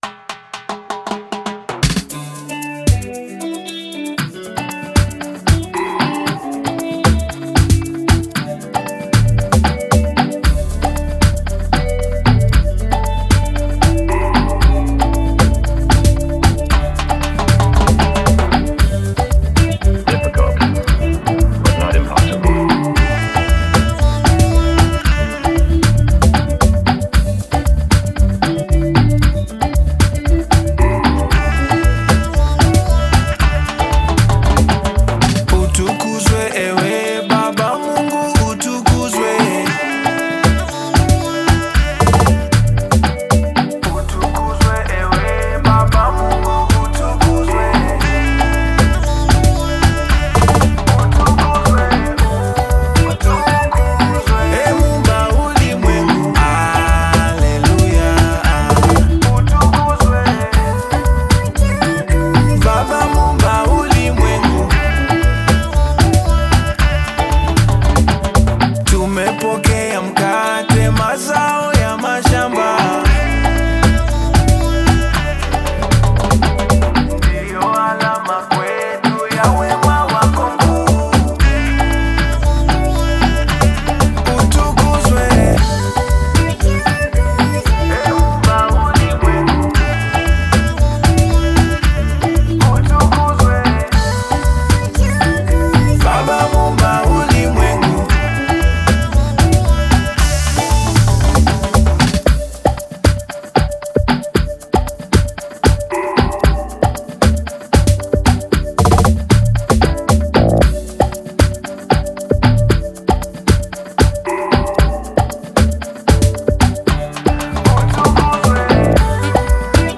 Tanzanian bongo flava artist singer and songwriter
African Music